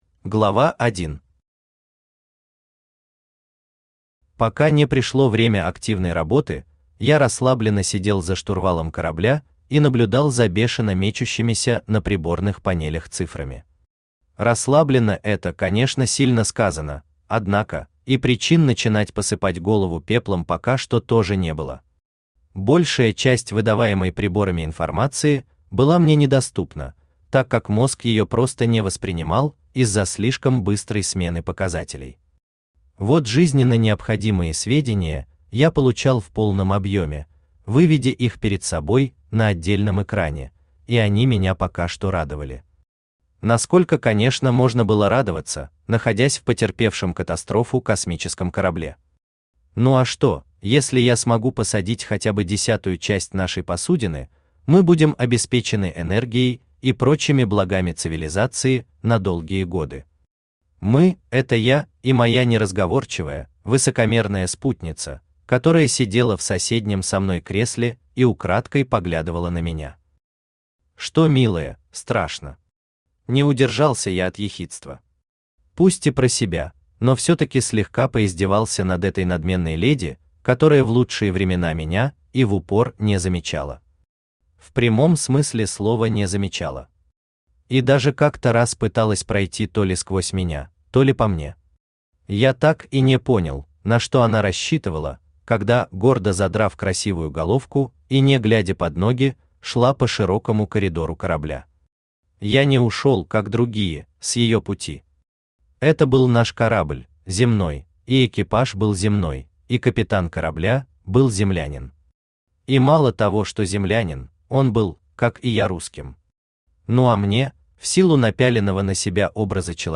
Аудиокнига Голубой восход | Библиотека аудиокниг
Aудиокнига Голубой восход Автор Андрей Викторович Пучков Читает аудиокнигу Авточтец ЛитРес.